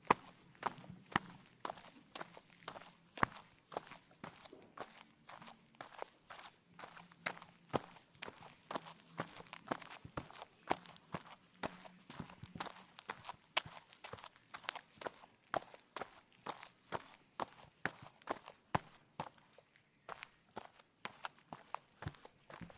Звуки шагов по асфальту
Шаги по горячему асфальту